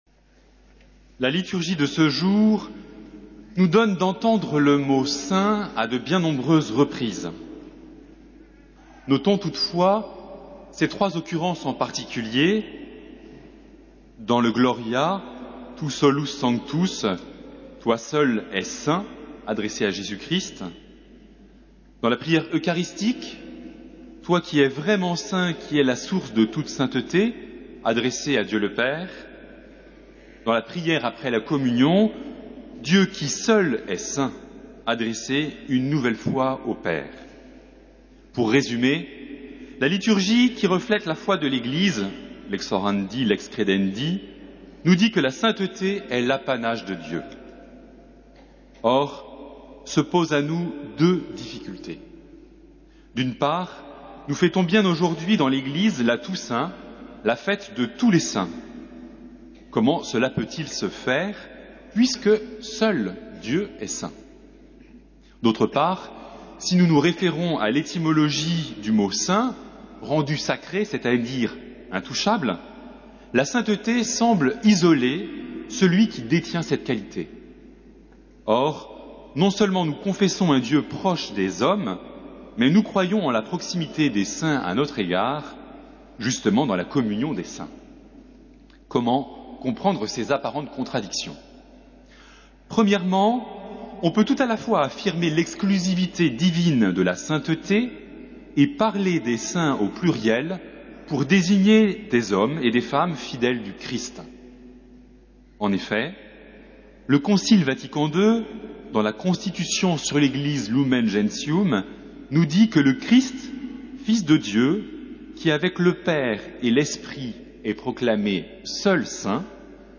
Homélie
Solennité de Tous les Saints